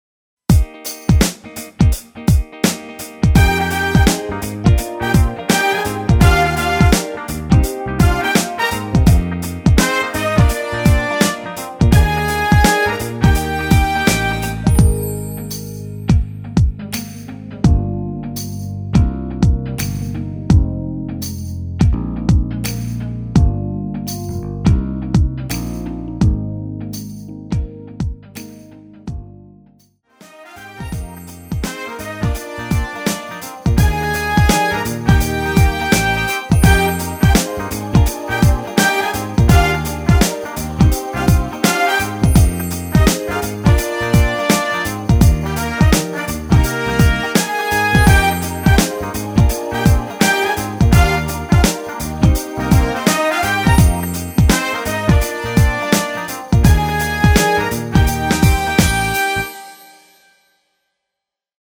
랩 부분 삭제한(+2)올린MR 입니다.
전주가 길어서 짧게 편곡 하였으며 브라스 악기 끝나고 15초쯤 노래 들어 가시면 됩니다.
Eb
앞부분30초, 뒷부분30초씩 편집해서 올려 드리고 있습니다.
중간에 음이 끈어지고 다시 나오는 이유는